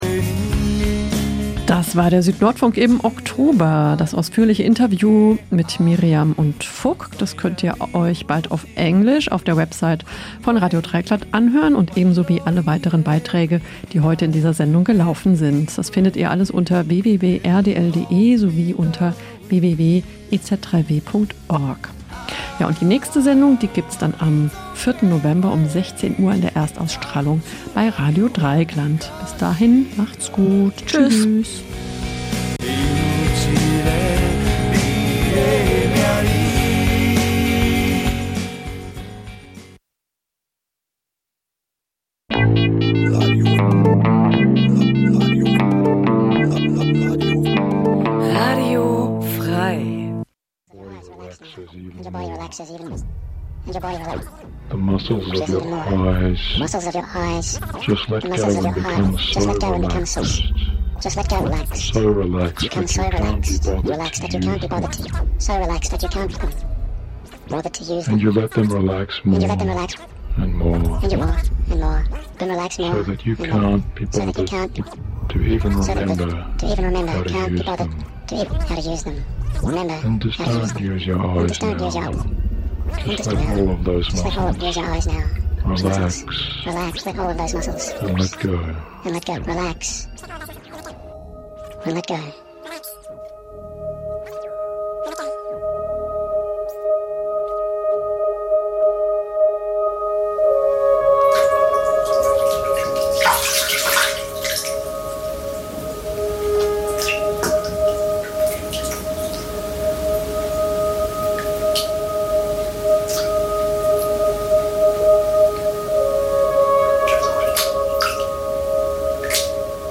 Die meist ruhigen und getragenen elektronischen Kl�nge, der entschleunigte Charakter dieser Musikrichtung, erfordern ein �bewusstes Sich-Einlassen� auf die Musik, einen quasi meditativen Akt, der als musikalische Alternative zur modernen Leistungsgesellschaft gesehen werden kann.